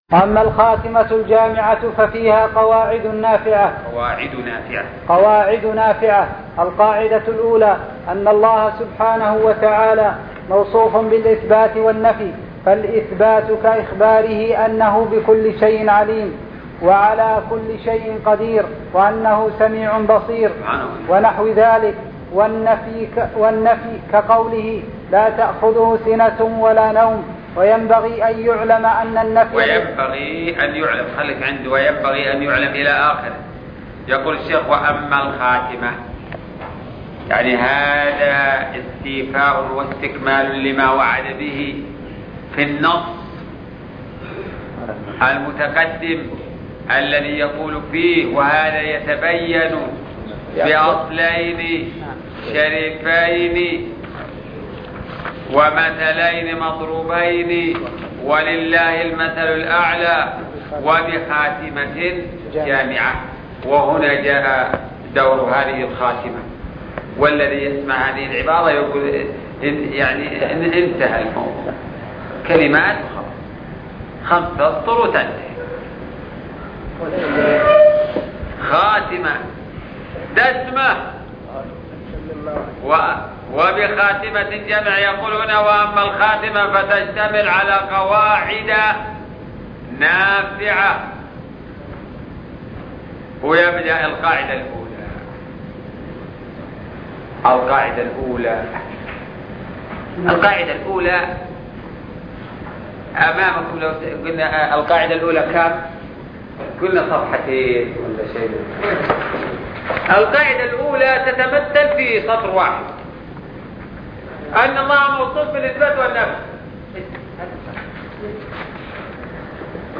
عنوان المادة شرح الرسالة التدمرية (17) الدرس السابع عشر تاريخ التحميل السبت 19 فبراير 2022 مـ حجم المادة 41.51 ميجا بايت عدد الزيارات 243 زيارة عدد مرات الحفظ 80 مرة إستماع المادة حفظ المادة اضف تعليقك أرسل لصديق